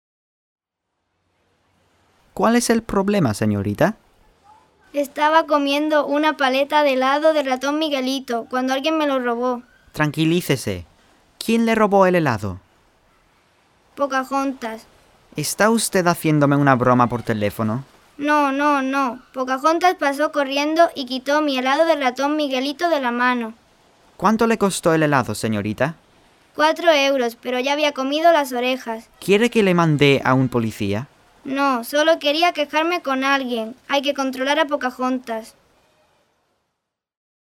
Whimsical Dialogues for Upper Level Classes
The Spanish enactment (mp3) can be played while the students read along.